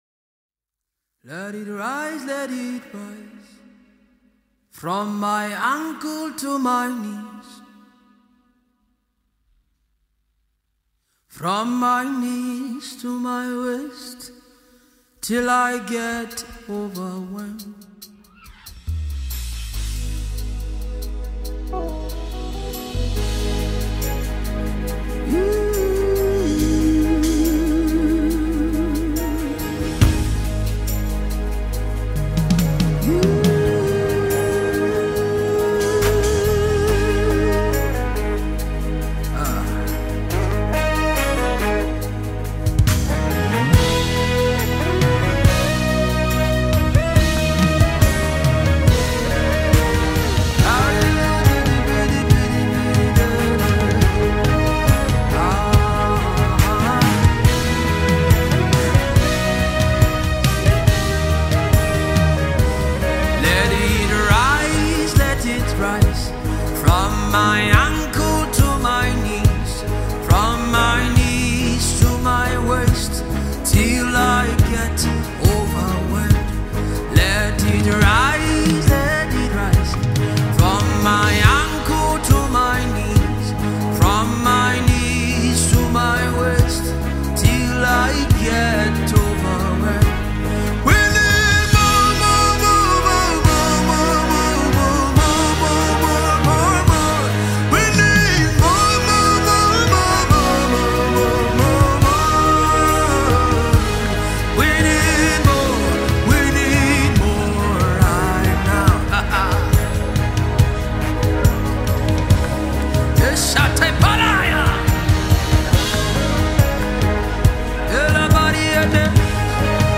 Home » Gospel